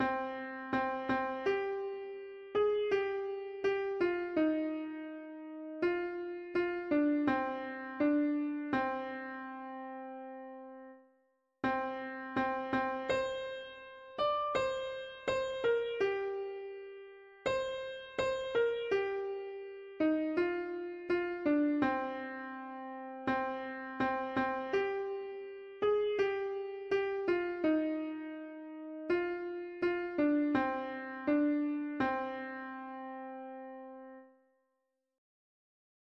Music: Plains Indian traditional, 1879 Key: C minor Meter: irregular